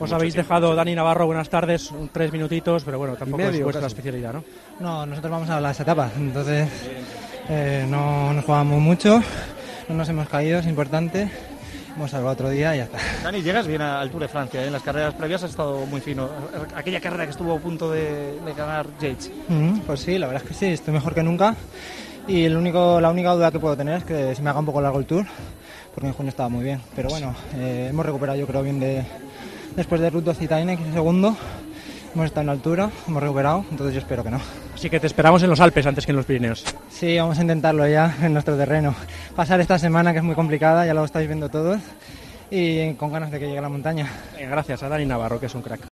El ciclista del Cofidis habla con los medios al término de la contrarreloj por equipos: "Estoy mejor que nunca y la única duda que tengo es que se me haga largo el Tour. Vamos a intentarlo en nuestro terreno, que pase lo más rápido"